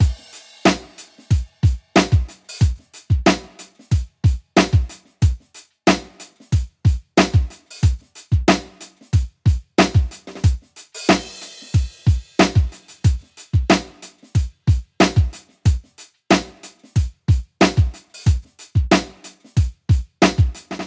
灵魂接触的七十年代老式节拍，92 BPM
描述：简单的节拍，复古的鼓声，直来直去。从我的电子鼓套装中演奏和录制。
Tag: 92 bpm Rock Loops Drum Loops 3.51 MB wav Key : Unknown